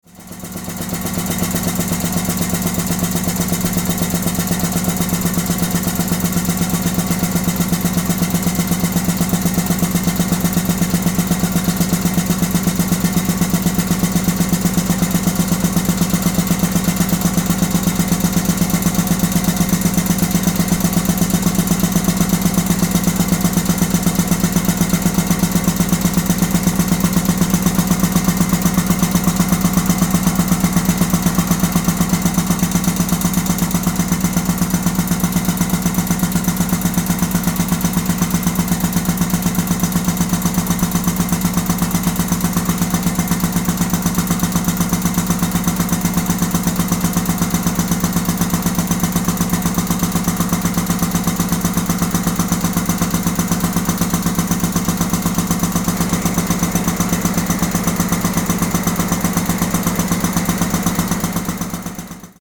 Air compressor
This air compressor, built by the Atlas company in Stockholm, resides next to a power generator, driven by a large hot bulb engine. The machinery in this part of the Pythagoras mechanical workshop was installed in the early 1930.
Machine type: Air compressor